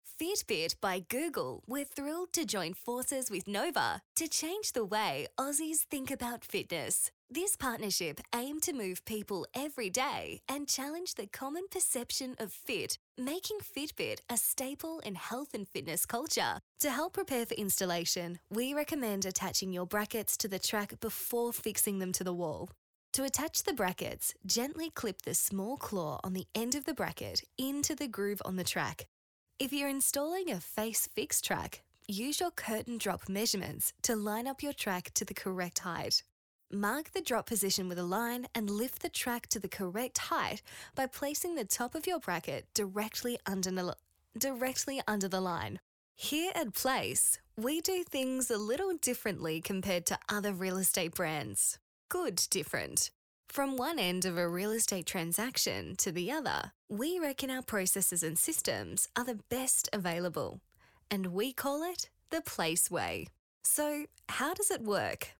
• Corporate Friendly
• Young
• Bright
• Fresh & Friendly
• Neumann TLM 103
• Own Home Studio